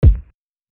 JEFFERDRIVE KICK.wav